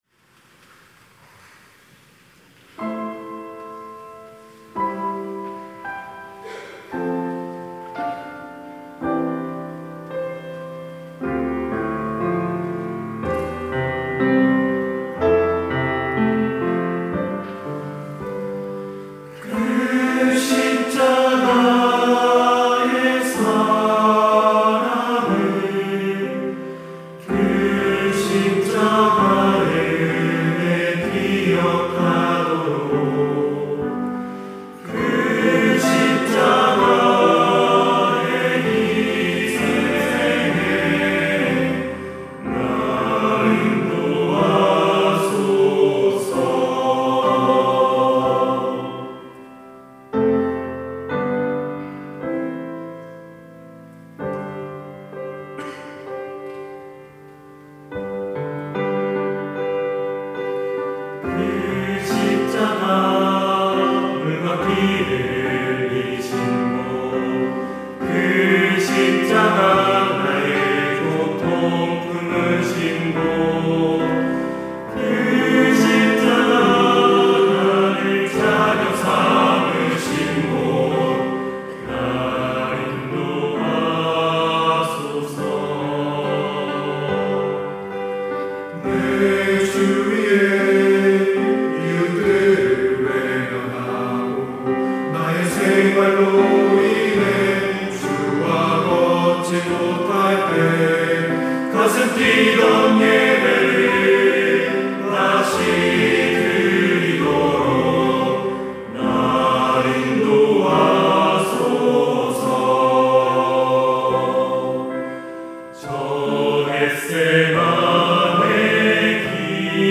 특송과 특주 - 사랑의 갈보리로